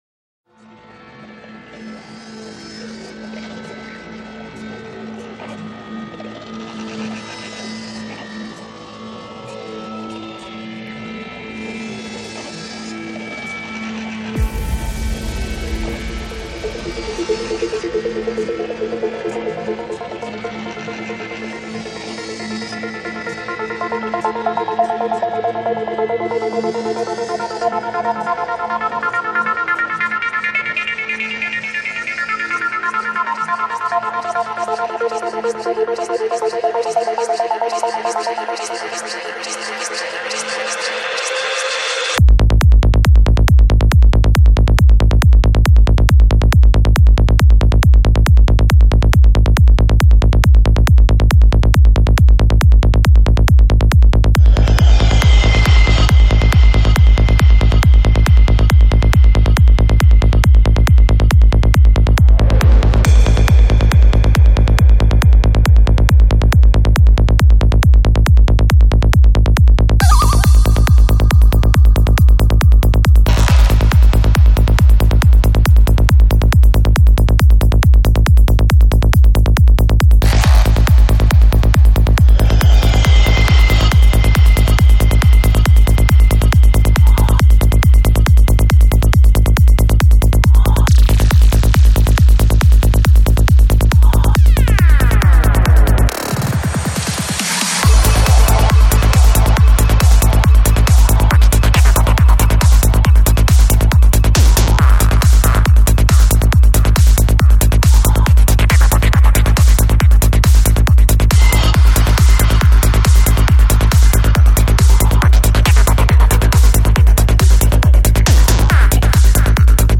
Жанр: Electronic
19:54 Альбом: Psy-Trance Скачать 8.02 Мб 0 0 0